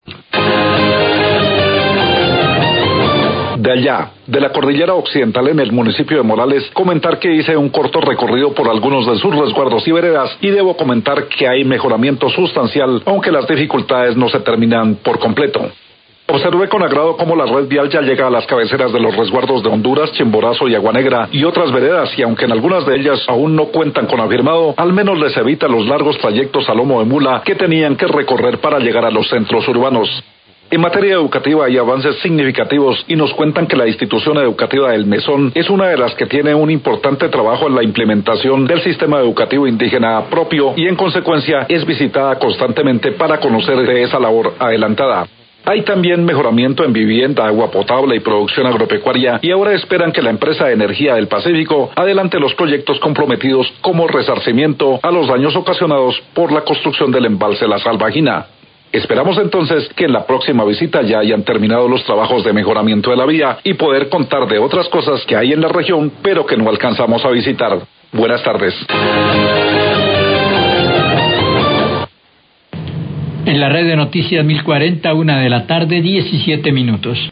Radio
Periodista comenta que ne reciente visita a La Salvajina encontró mejoramiento en varios aspectos como vias y educación. Agrega que  se espera que Epsa adelante los proyectos comprometidos como compensación a los daños ocasionados por la construcción del embalse La Salvajina